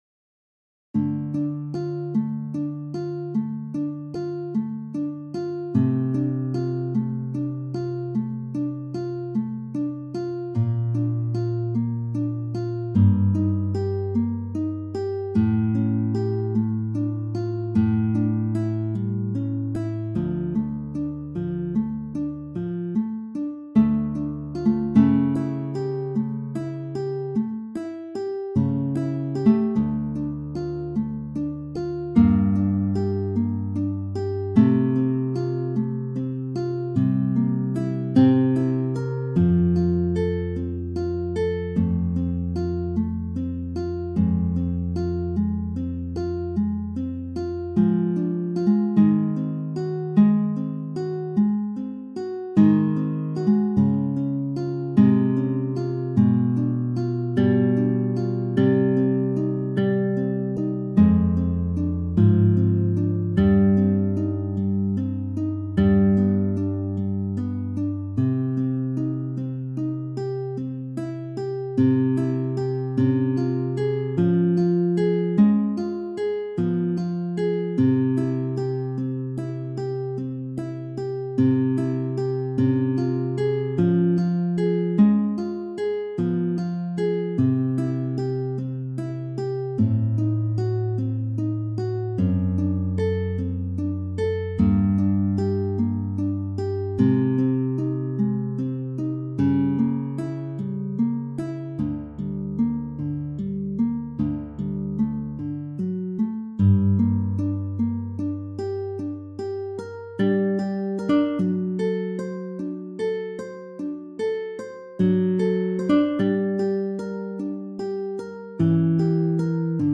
beethoven-tarrega-moonlight-sonata1-guitar.mp3